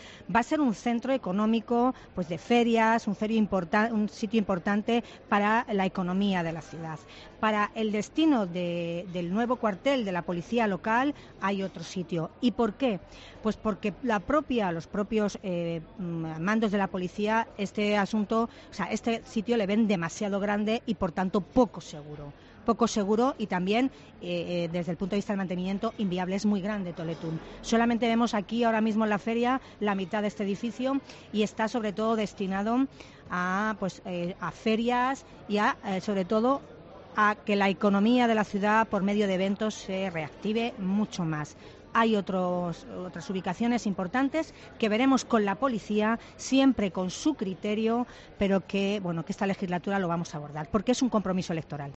Declaraciones de Tolón en la inauguración hoy de FARCAMA, la Feria de Artesanía de Castilla-La Mancha, que desde este viernes, 4 de octubre, y hasta el día 13 de ese mismo mes, está instalada precisamente en ese edificio y que celebra su XXXIX edición, la primera de carácter internacional por la presencia de artesanos de otros países.